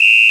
PRC WHISTL01.wav